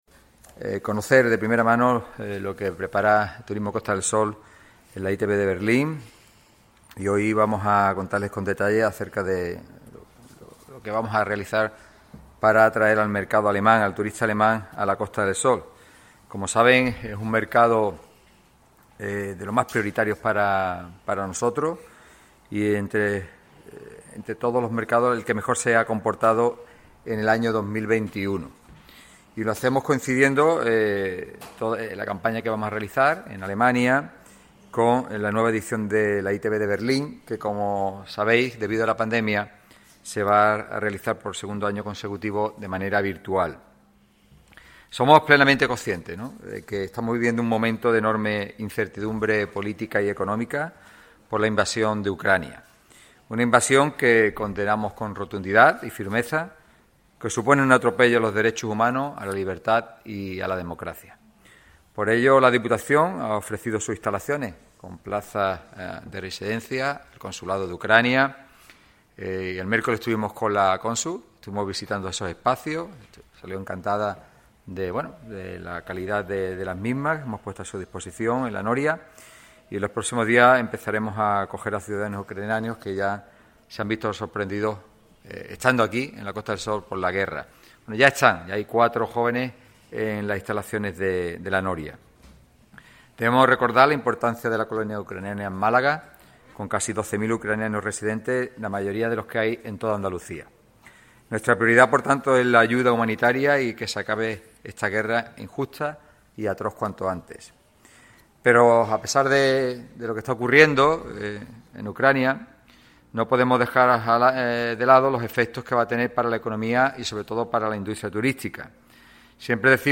Así lo ha puesto de manifiesto el presidente de la Diputación de Málaga y de Turismo Costa del Sol, Francisco Salado, en una rueda de prensa en la que además ha hecho balance de situación de la coyuntura turística actual y de la incertidumbre generada por la guerra de Ucrania.